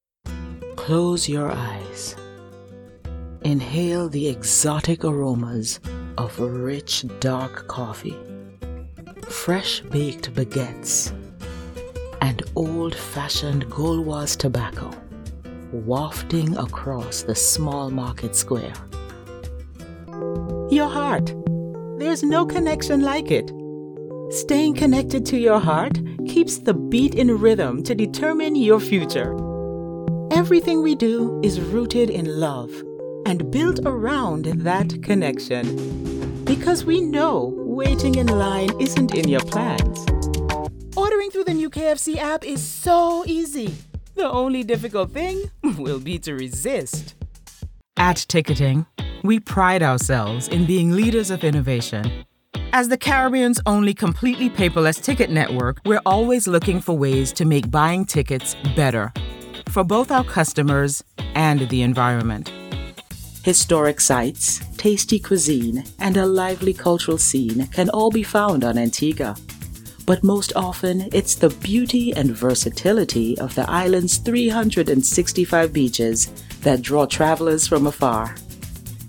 Inglés (Caribe)
Confiable
Cálido
Autoritario